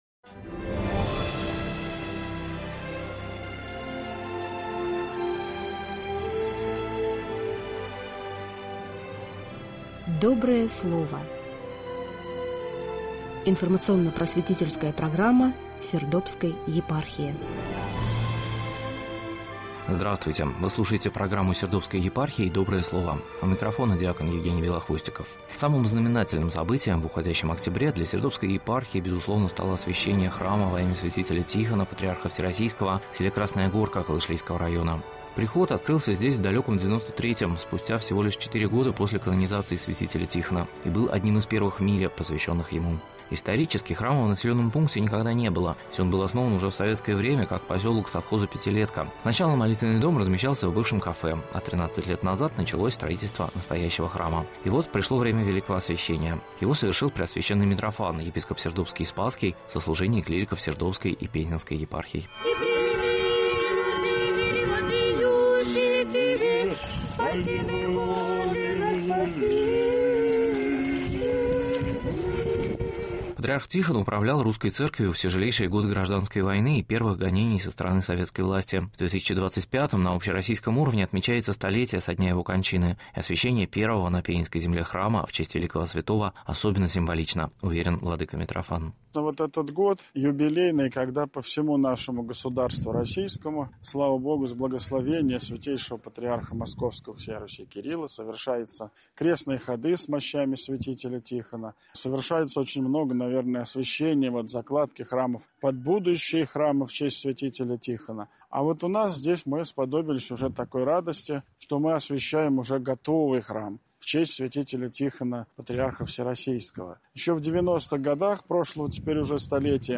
подготовил репортаж с места события.